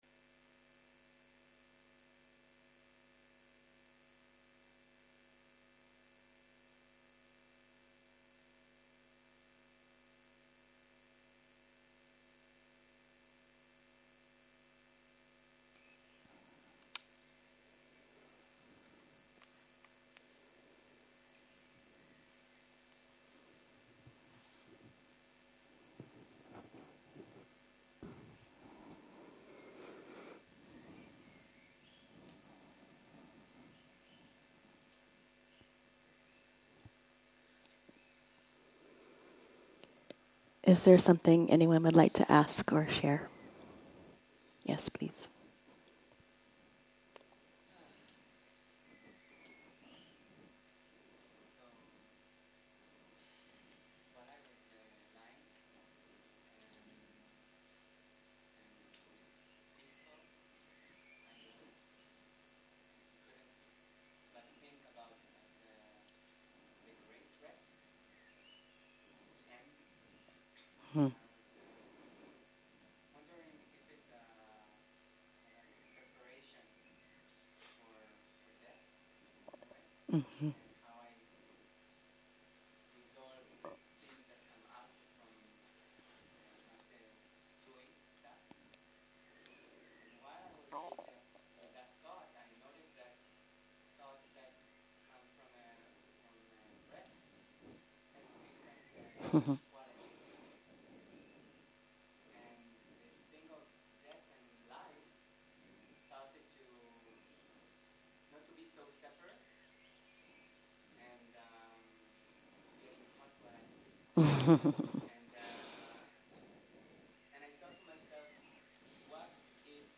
08.03.2023 - יום 5 - צהרים - שיחת דהרמה - הקלטה 9
Dharma Talks שפת ההקלטה